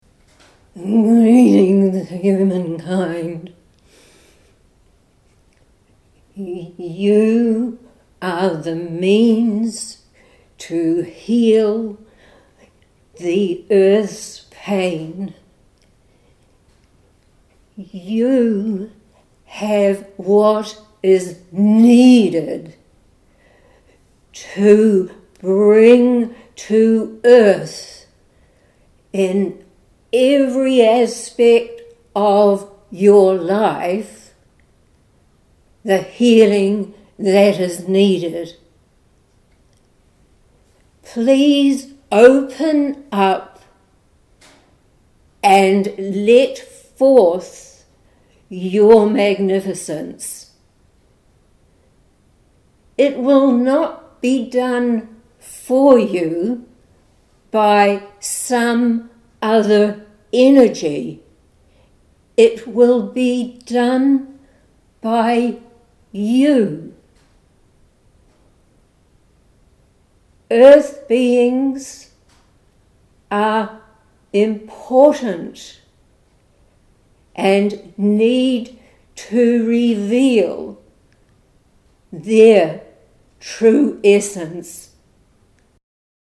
Posted in Audio recording, Channelled messages, Metaphysical, Spirituality, Trance medium